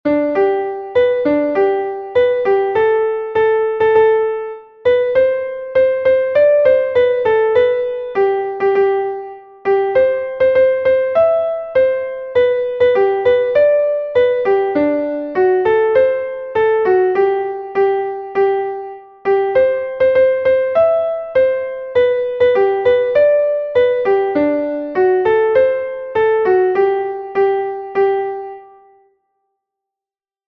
Die Melodie ist eingängig und fröhlich, was das Lied besonders populär macht, vor allem bei Gruppenaktivitäten wie Wanderungen oder Lagerfeuern.
Einstimmige Melodie im Violinschlüssel, G-Dur, 2/4-Takt, mit der 1.
Text und Melodie: nach einem schwedischen Volkslied
im-fruehtau-zu-berge_klavier_melodiemeister.mp3